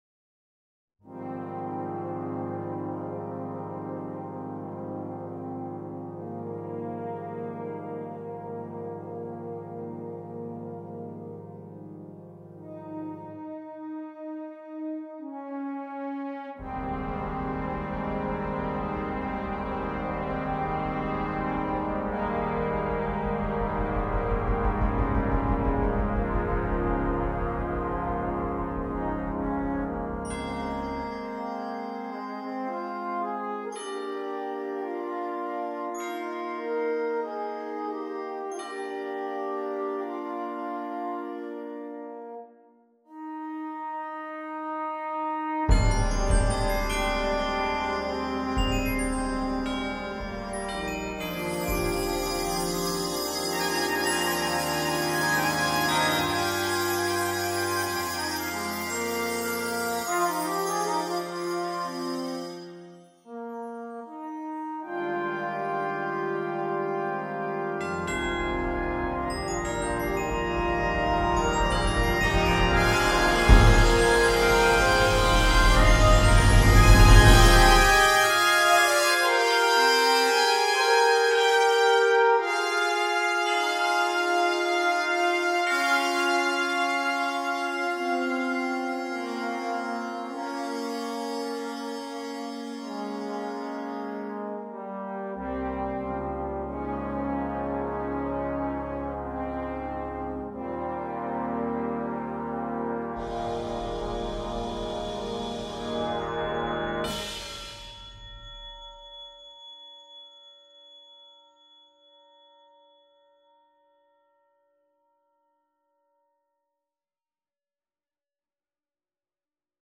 Solo with Brass Ensemble